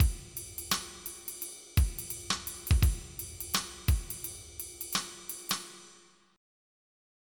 MIDI Music File
2 channels
jazz.mp3